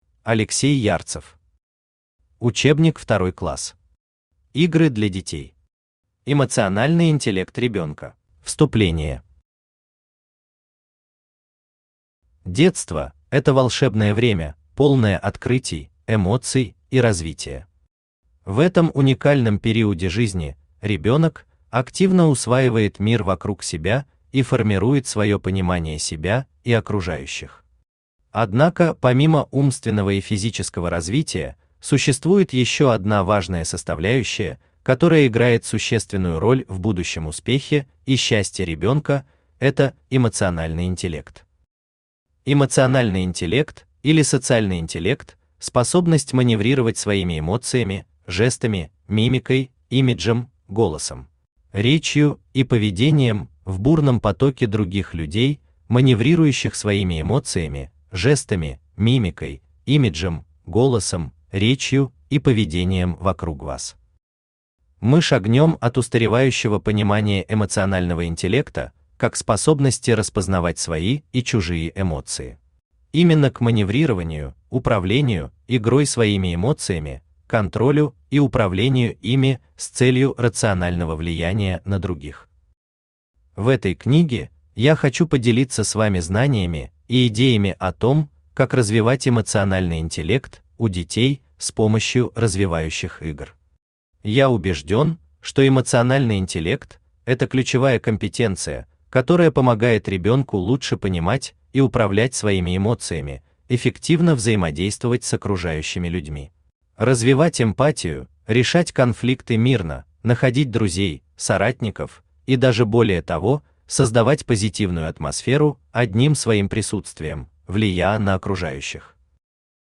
Аудиокнига Учебник 2 класс. Игры для детей. Эмоциональный интеллект ребенка | Библиотека аудиокниг